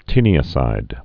(tēnē-ə-sīd)